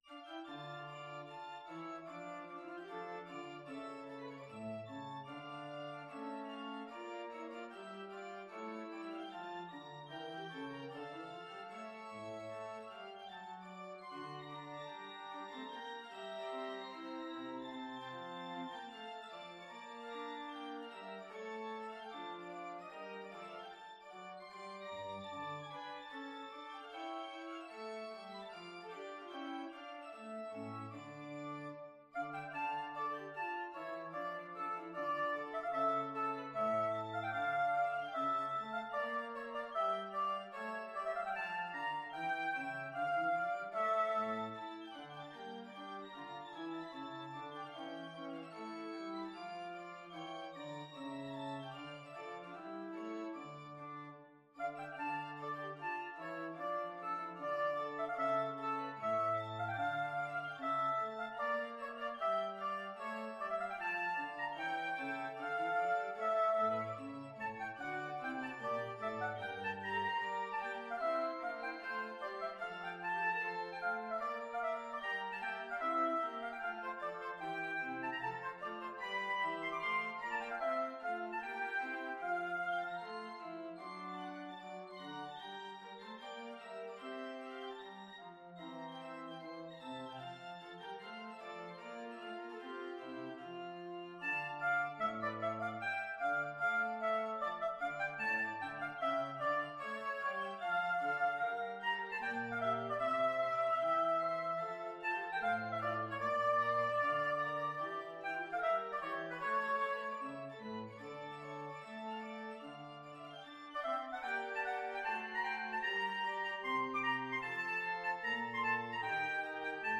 Albinoni, Tomaso - Oboe Concerto in D minor, Op.9 No.2 Free Sheet music for Oboe and Ensemble
Oboe Violin 1 Violin 2 Viola Bass Harpsichord
Style: Classical
oboe-concerto-in-d-minor-op-9-2.mp3